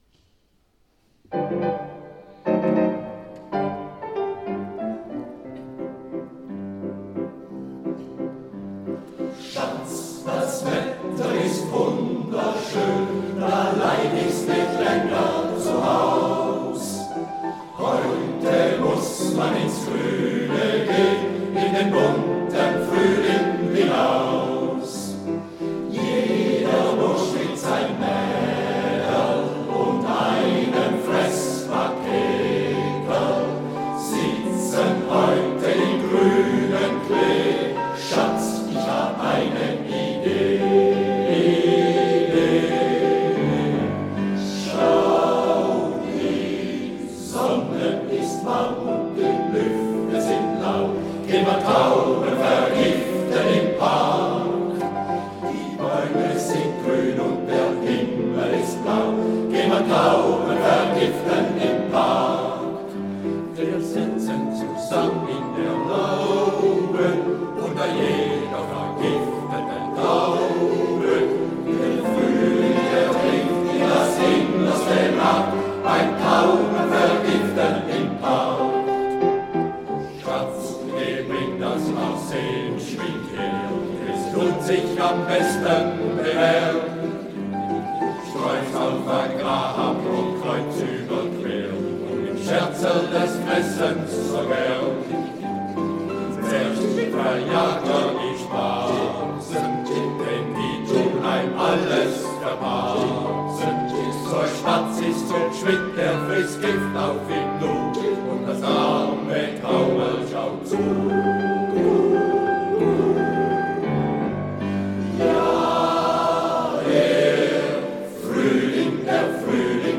Hitzkirch 2019